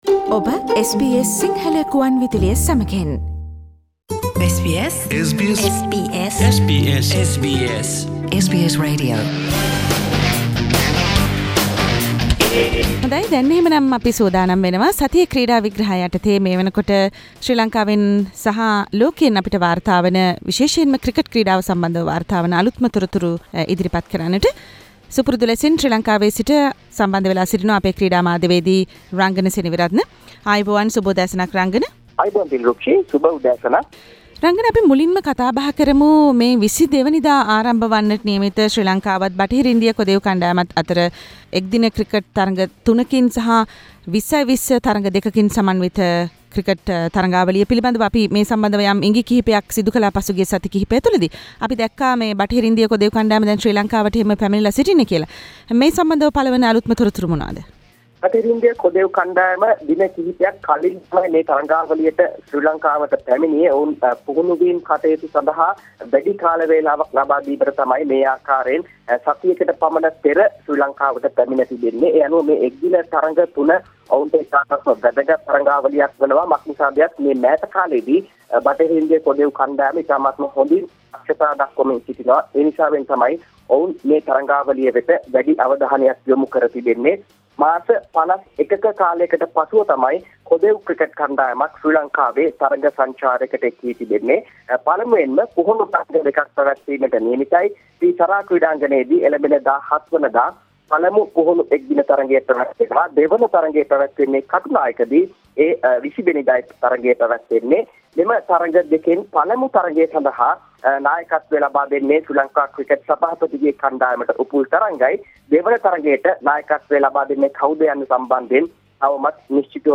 weekly sports wrap Source: SBS Sinhala Radio